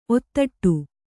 ♪ ottaṭṭu